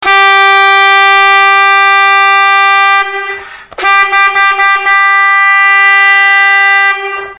Acme 566 – Plastic Horn, black
Gjutet, stryktåligt och högljutt signalhorn.
Very loud and far carrying frequency.
All metal hand tuned reed.
horn_566.mp3